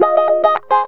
GTR 5 A#M110.wav